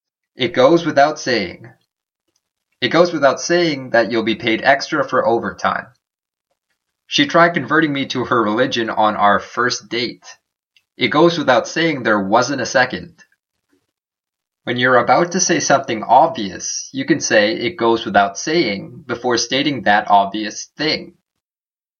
英語ネイティブによる発音は下記のリンクをクリックしてください。
itgoeswithoutsaying.mp3